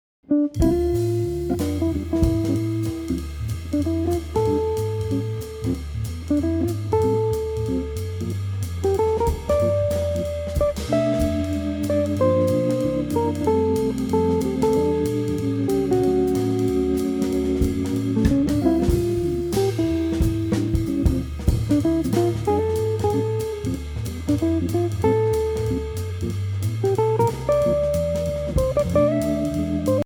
Jazz guitarist